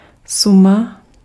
Ääntäminen
Tuntematon aksentti: IPA: /nɔ̃bʁ/